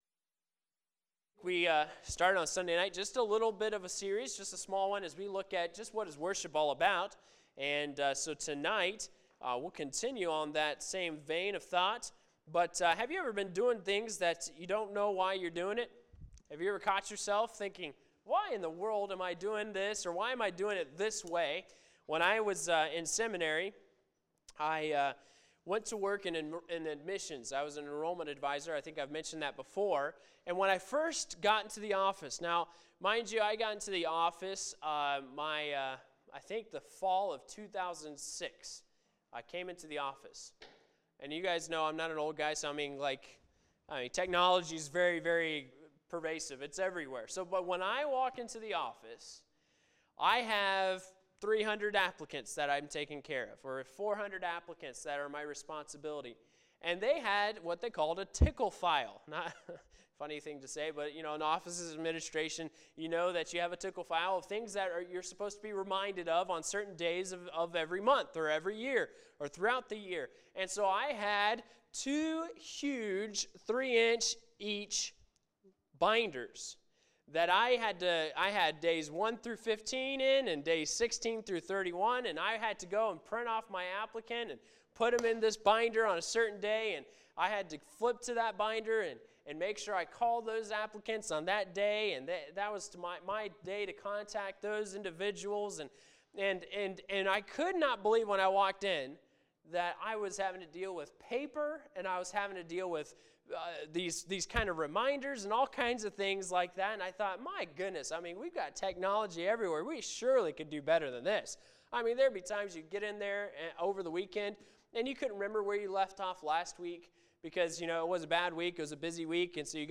Sermons | Anthony Baptist Church